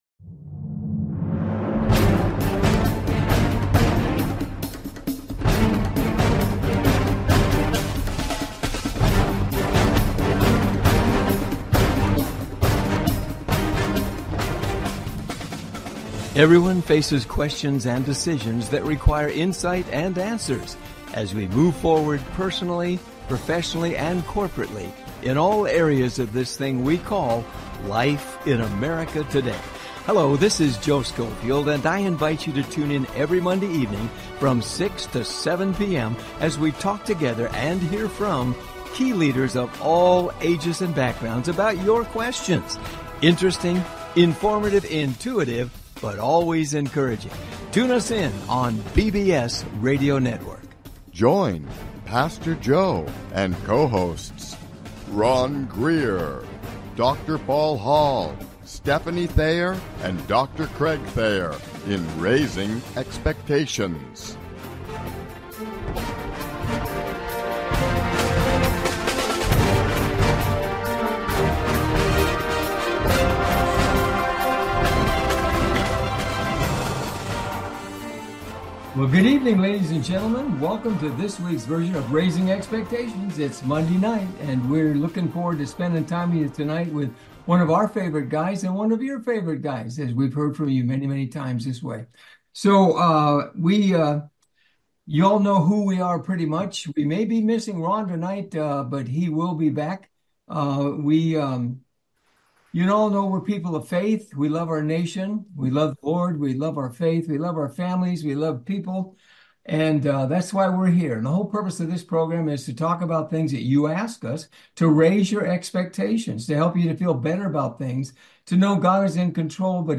Raising Expectations Talk Show